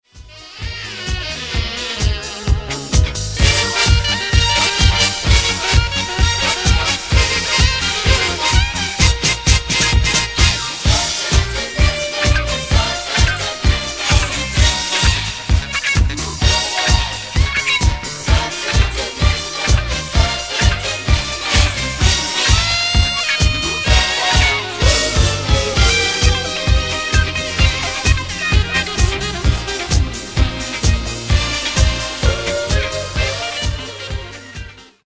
Genre:   Disco Soul